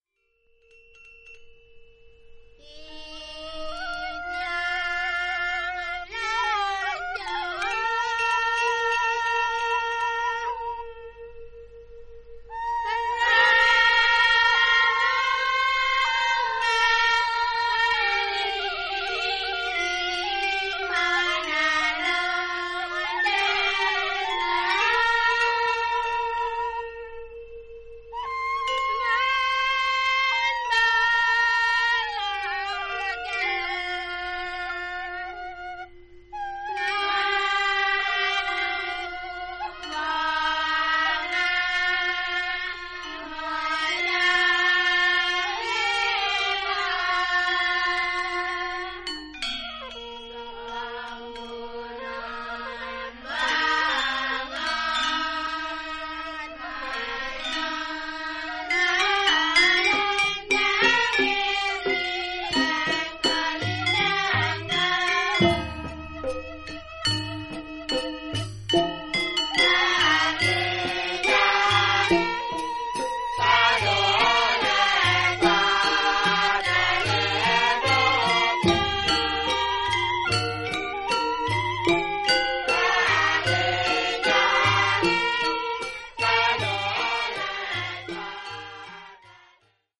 バリの伝統的古典音楽をコンパイルしたオムニバス。
A2 Musicans And Singers Of Teges - Janger "Titian Latjur"